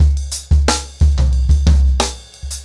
InDaHouse-90BPM.3.wav